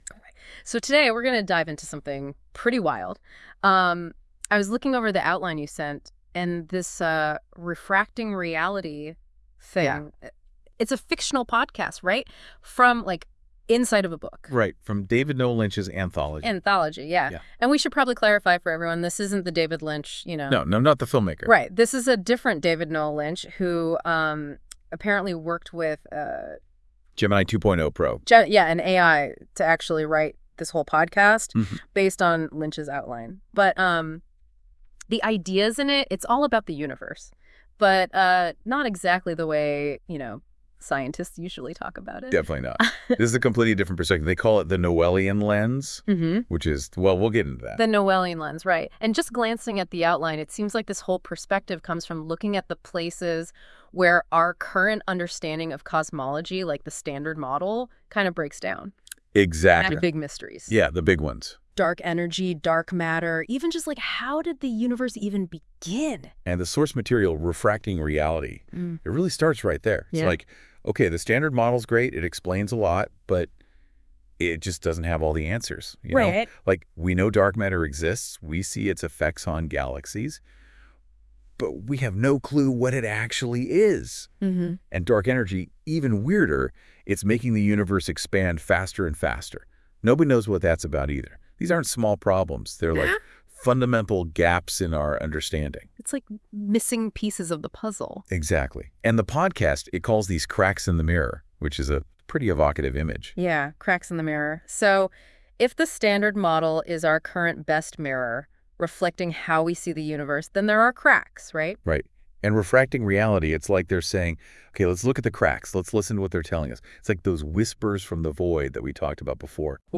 (Sound: A low, resonant hum begins, subtly unsettling, like a distant machine coming to life. It’s punctuated by occasional, almost imperceptible glitches – a skip in a record, a flicker of static.)